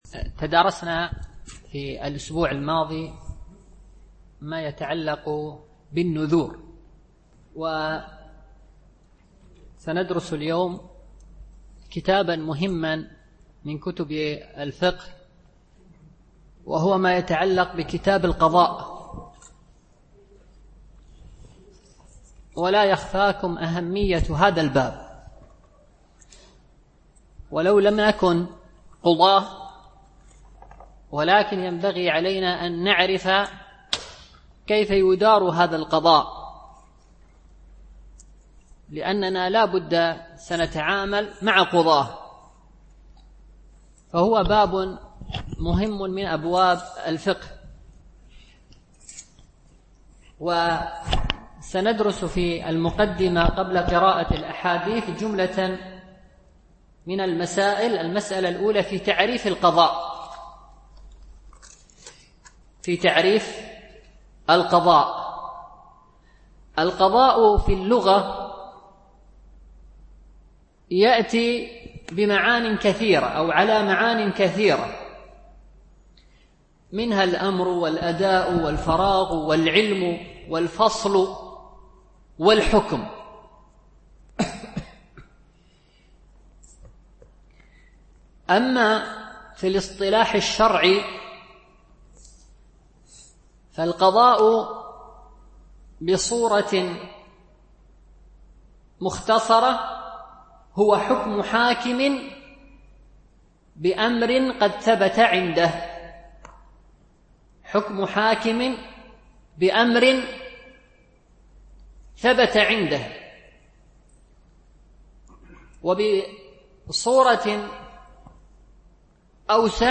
شرح عمدة الأحكام - الدرس 87 ( الحديث 371 - 376 )